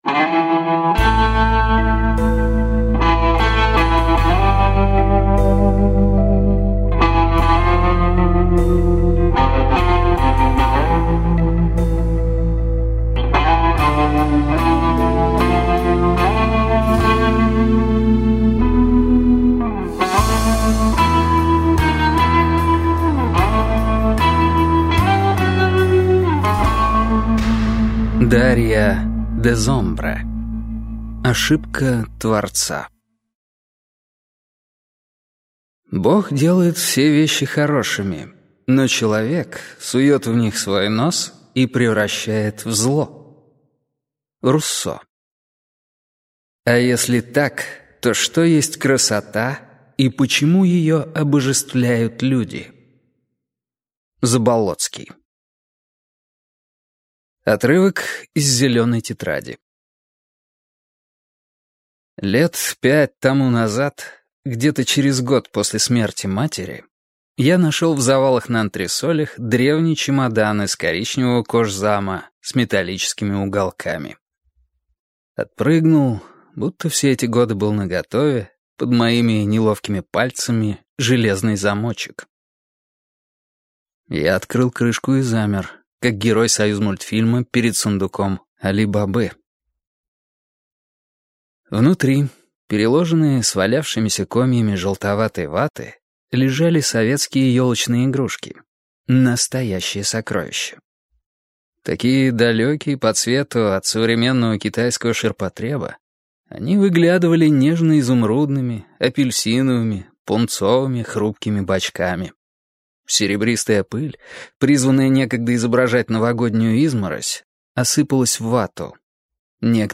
Аудиокнига Ошибка Творца - купить, скачать и слушать онлайн | КнигоПоиск